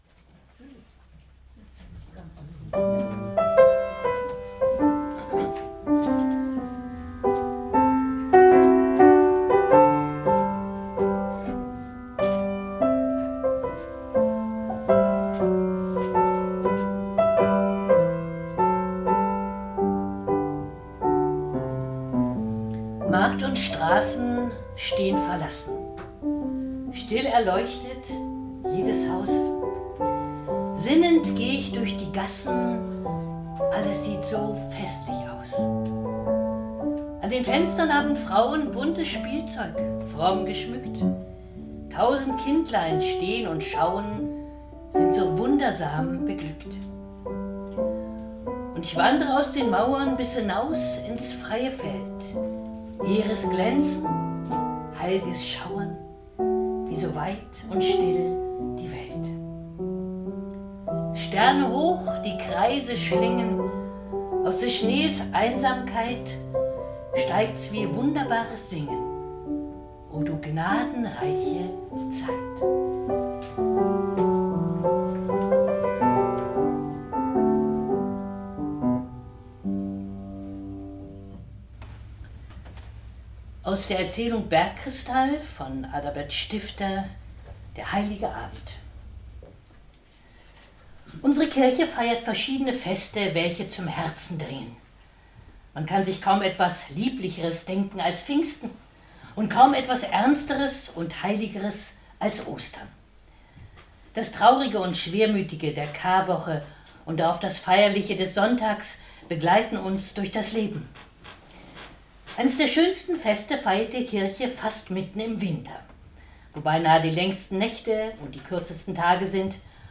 Mitschnitt einer öffentlichen Veranstaltung (MP3, Audio)